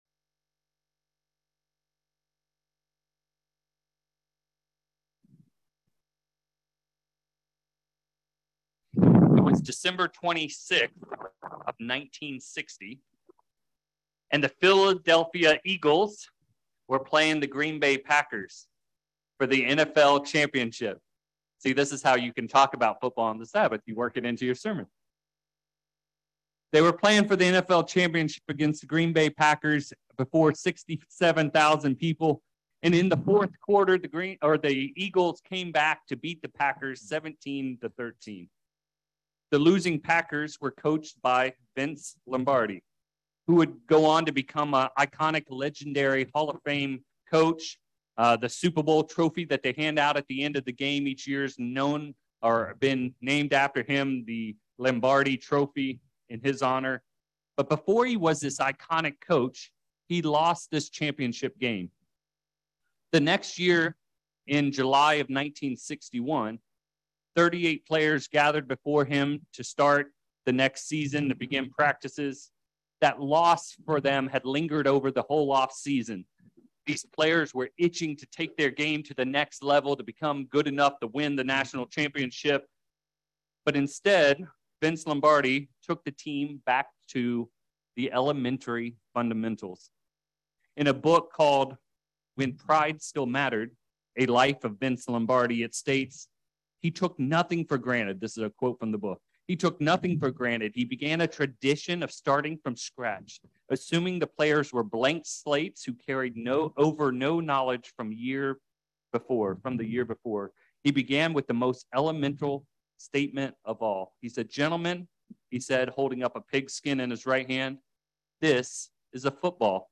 In this first sermon in a series on Christian Fundamentals, we will review the basics of prayer. What did Christ team us about what we should do and what we should avoid when it comes to prayer? How long does a prayer need to be effective?